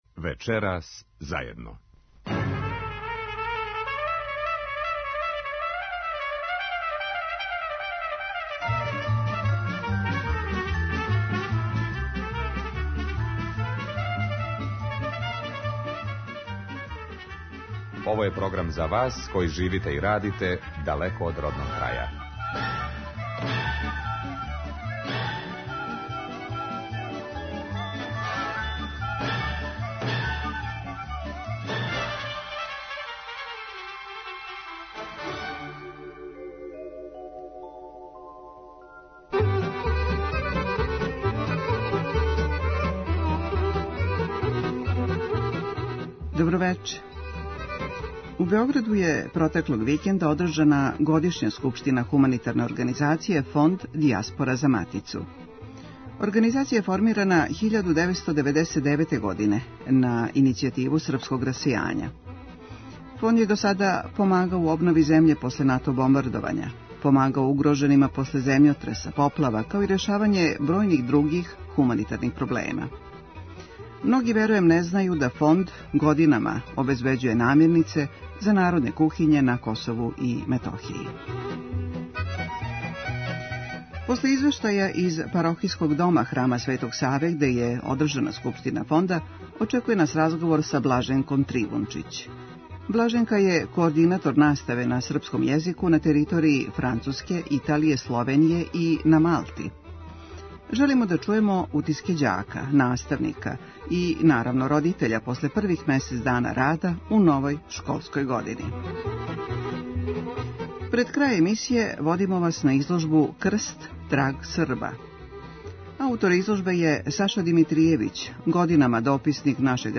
Емисија магазинског типа која се емитује сваког петка од 21 час.